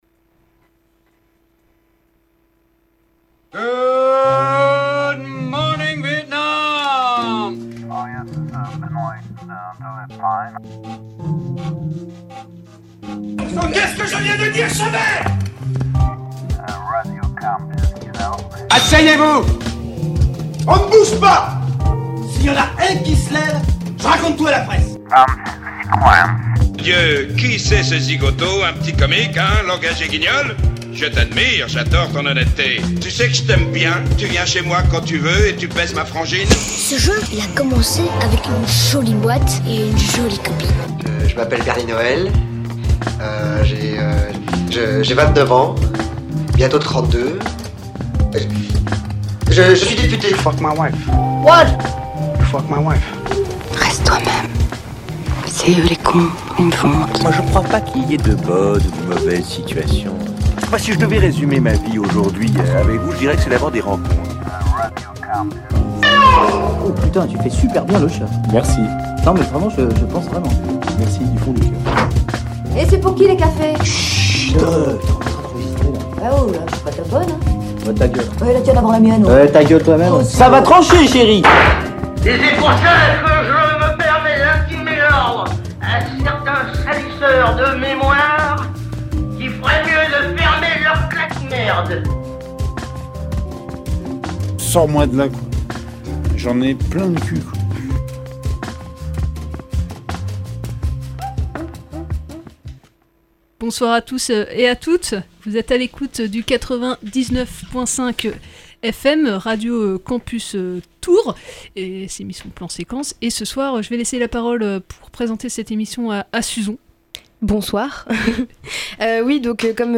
Plan Séquence | ITW Mounia Meddour pour Papicha / Woman / Alice Guy / Carole Roussopoulos / Agnès Varda - Radio Campus Tours - 99.5 FM
Dans cette émission, vous pourrez dans un premier temps, (ré)entendre Mounia Meddour, réalisatrice du film Papicha.